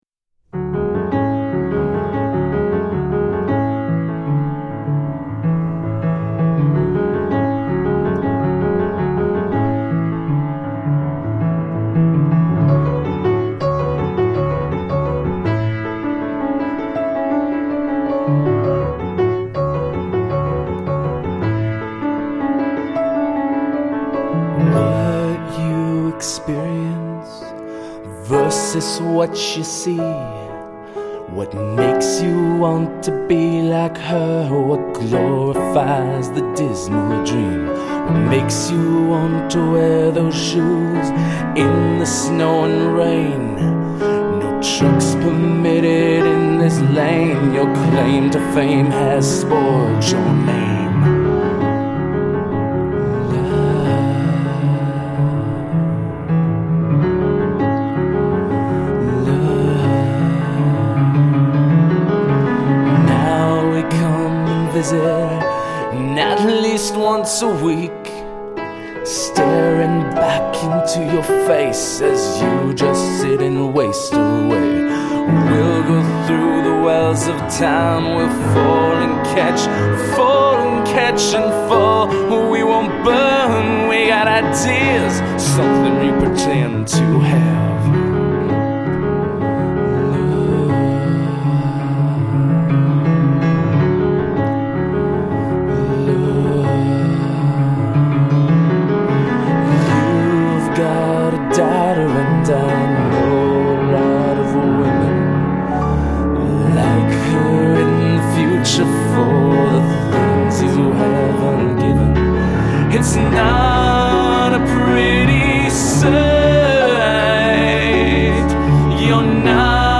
solo piano and vocal